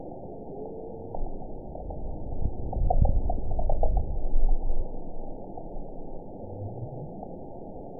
event 922244 date 12/28/24 time 21:37:56 GMT (5 months, 3 weeks ago) score 9.47 location TSS-AB03 detected by nrw target species NRW annotations +NRW Spectrogram: Frequency (kHz) vs. Time (s) audio not available .wav